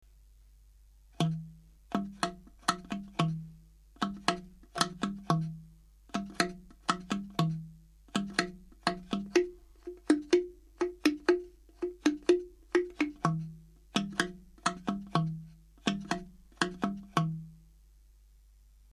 竹カリンバ｜手づくり楽器 ～ 音 遊 具 ～